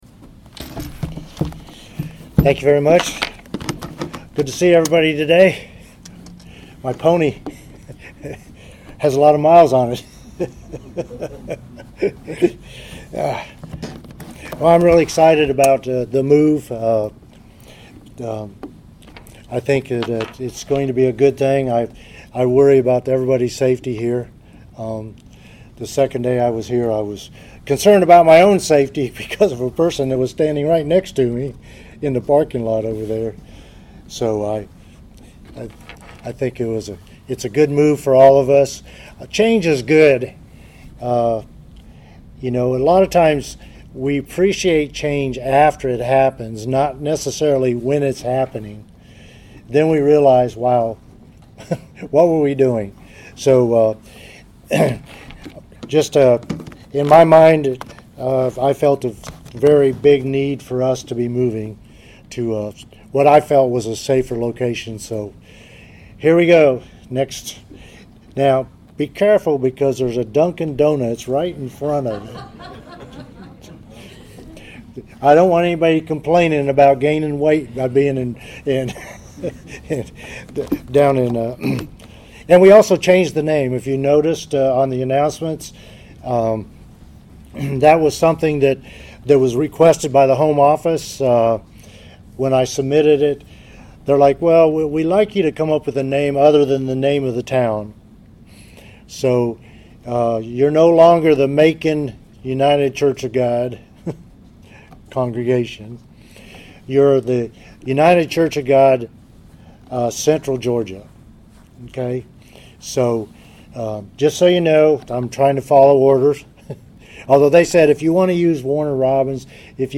Sermon
Given in Columbus, GA Central Georgia